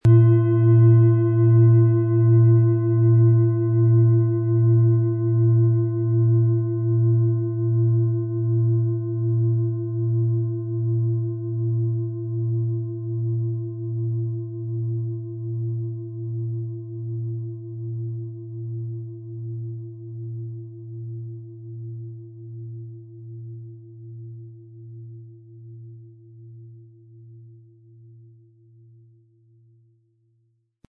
Wie klingt diese tibetische Klangschale mit dem Planetenton Chiron?
PlanetentöneChiron & Mond
MaterialBronze